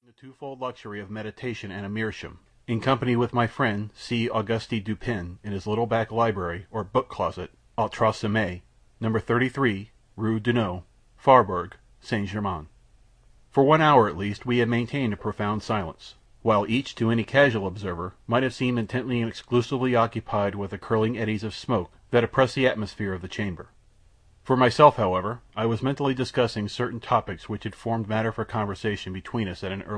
Audio Book
Easy Listening Short Story
Lebrivox Recording